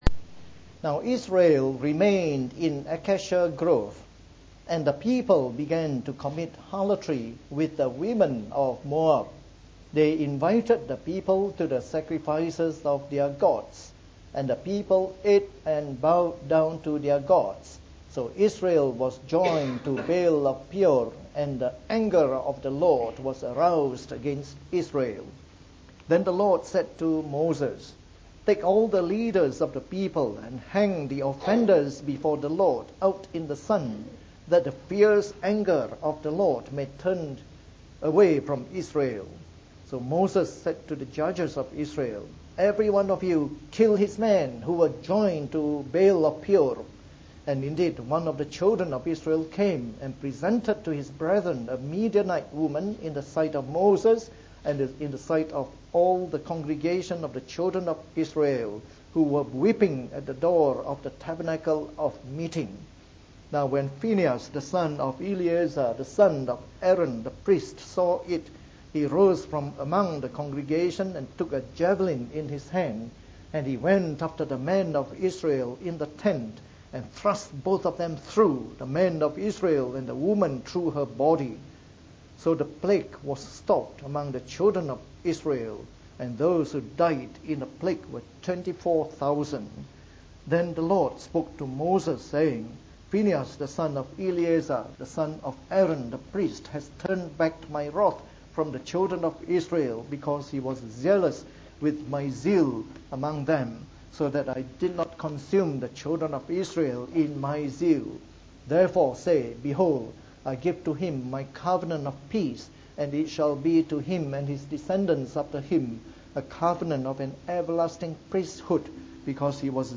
From our series on the “Book of Numbers” delivered in the Morning Service.